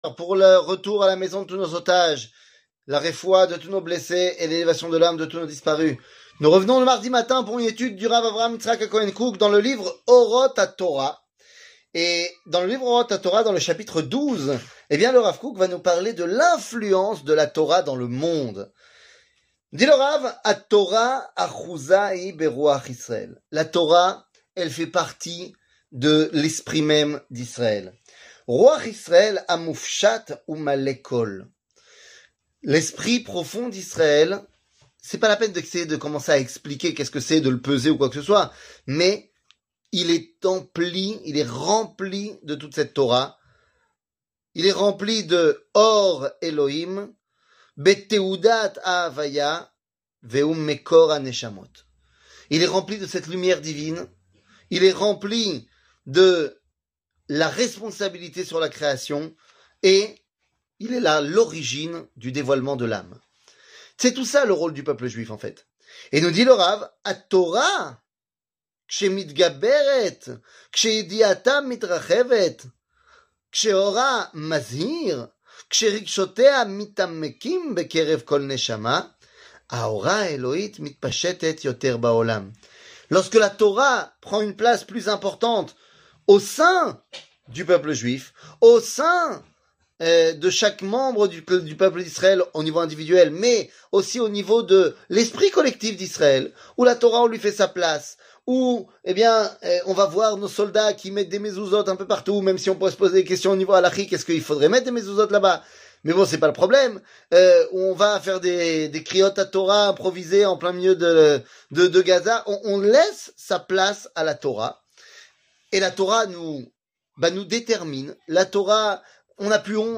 Rav Kook, Orot Atorah, La Torah se propage 00:04:17 Rav Kook, Orot Atorah, La Torah se propage שיעור מ 21 נובמבר 2023 04MIN הורדה בקובץ אודיו MP3 (3.91 Mo) הורדה בקובץ וידאו MP4 (5.79 Mo) TAGS : שיעורים קצרים